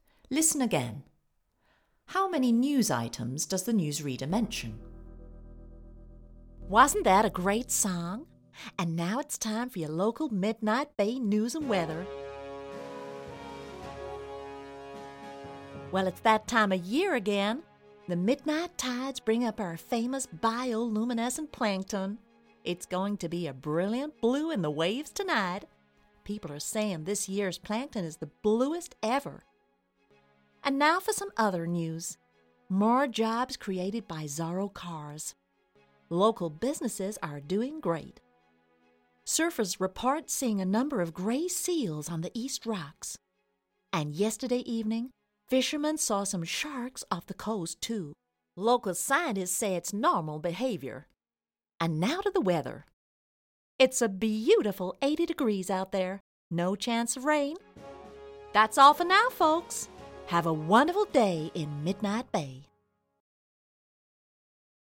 British English Speaker with a wide range of regional and international accents. Young to middle-age.
Sprechprobe: eLearning (Muttersprache):
da MIDNIGHT BAY - NEWS REPORT.mp3